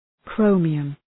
Προφορά
{‘krəʋmıəm}